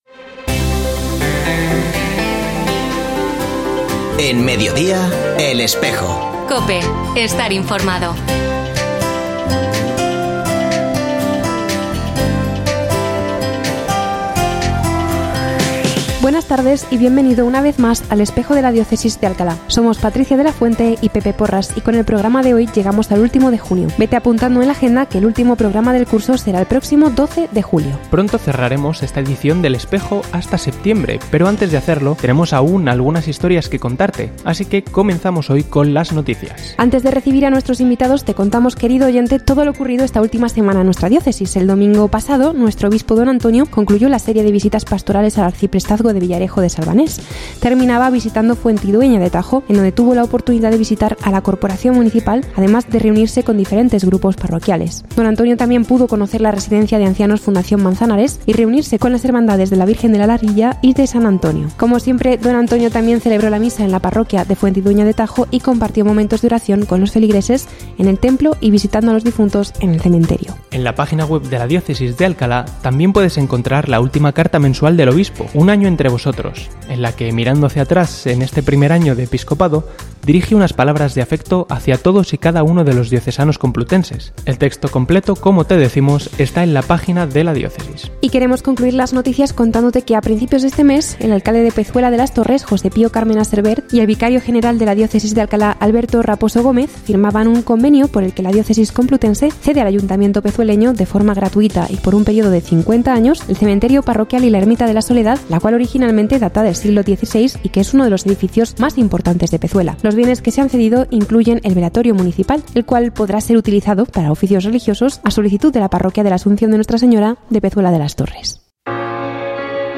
Escucha otras entrevistas de El Espejo de la Diócesis de Alcalá
Ofrecemos el audio del programa de El Espejo de la Diócesis de Alcalá emitido hoy, 28 de junio de 2024, en radio COPE. Este espacio de información religiosa de nuestra diócesis puede escucharse en la frecuencia 92.0 FM, todos los viernes de 13.33 a 14 horas.